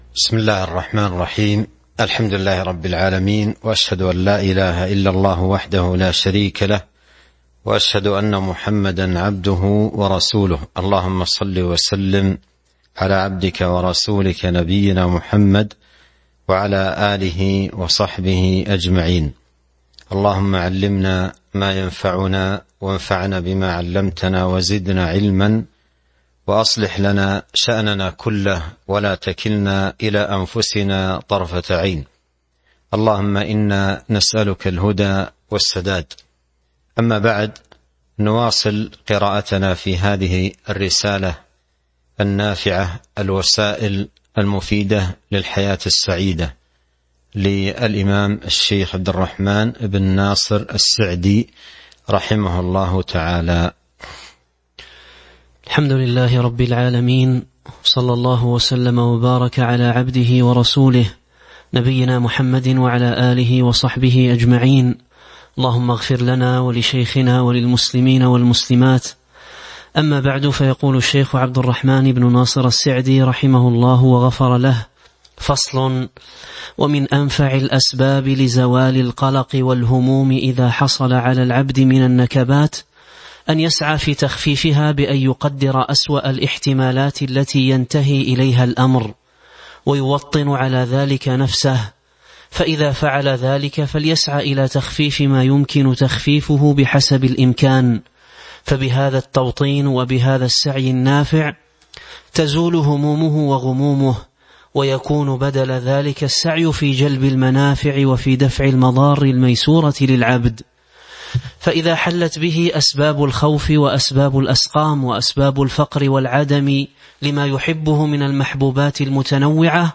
تاريخ النشر ١٥ رمضان ١٤٤٢ المكان: المسجد النبوي الشيخ